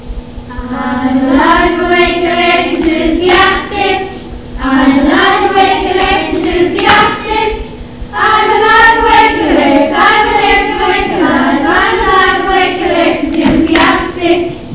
Click on any of the following songs to hear us singing: